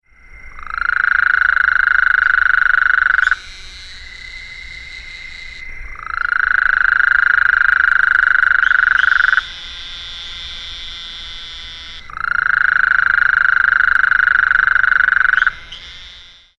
Gulf Coast Toad - Incilius nebulifer
Advertisement Calls
The call of the Gulf CoastToad is a short flat raspy trill, lasting 2 - 6 seconds.
soundThis is a 17 second recording of 3 advertisement calls of a single Gulf Coast Toad calling at night from the shallow edge of a small pond in Bastrop County, Texas shown to the right. The call of a Narrow-mouthed toad can be heard in the background, along with distant Houston Toads.